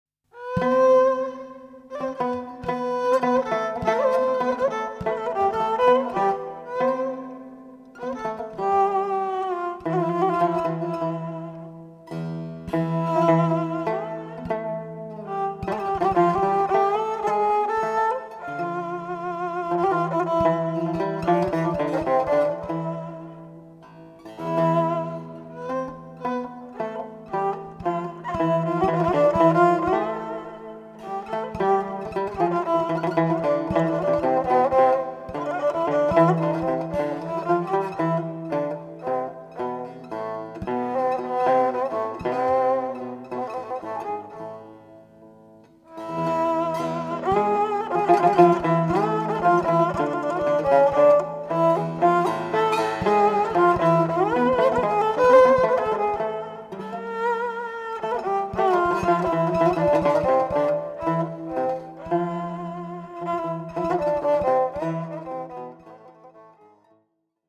Genre: Turkish & Ottoman Classical.
Tanbur
Classical Kemençe